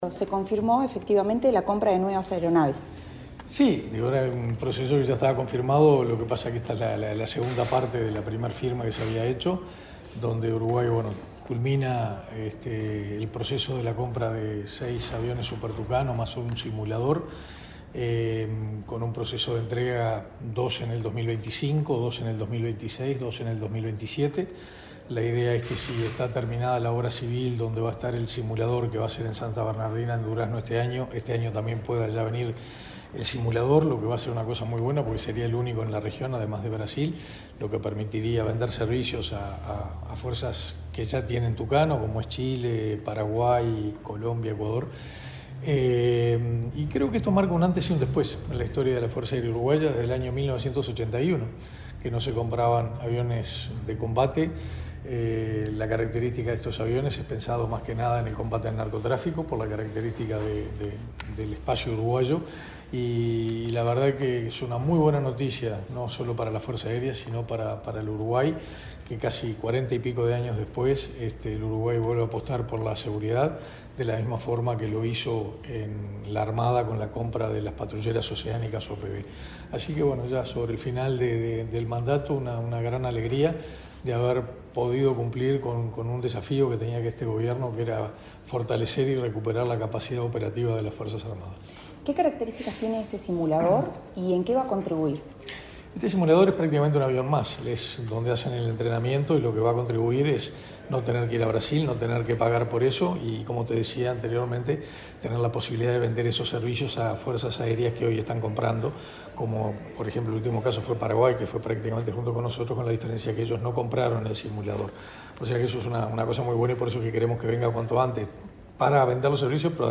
Entrevista al ministro de Defensa, Armando Castaingdebat